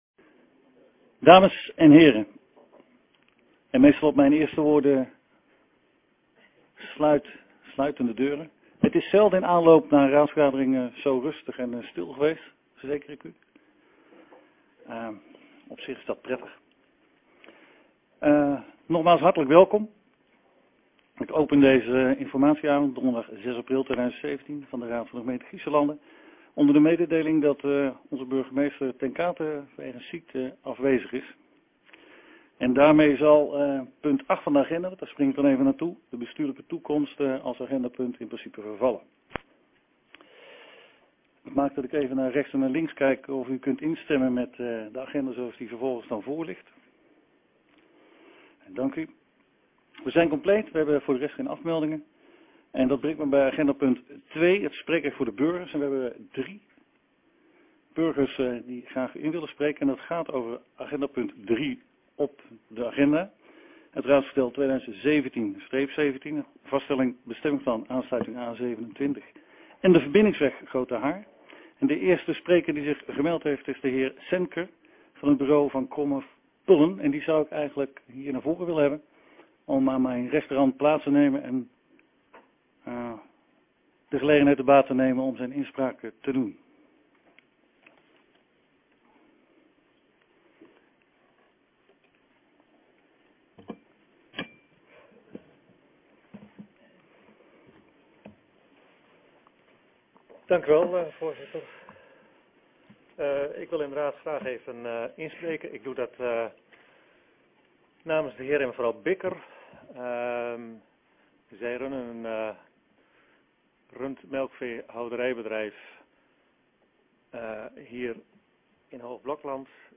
Hoornaar, gemeentehuis - raadzaal